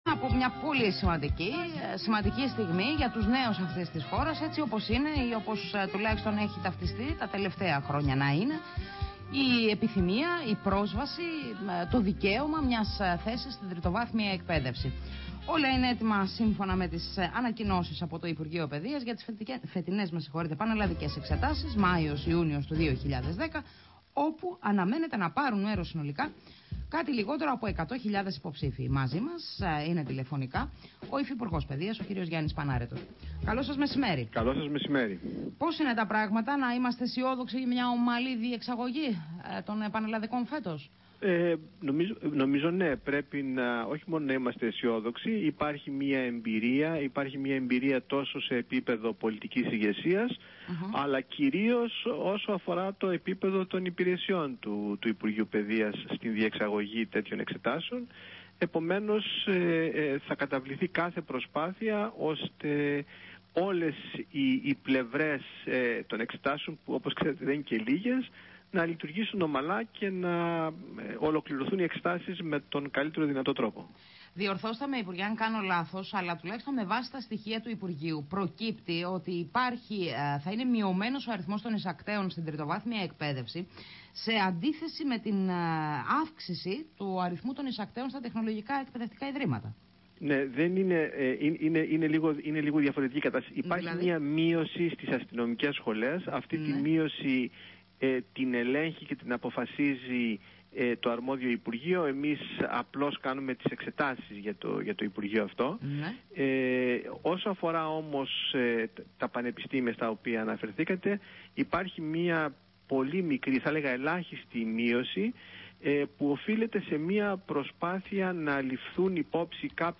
Συνέντευξη στο ραδιόφωνο της ΝΕΤ (30/3/2010)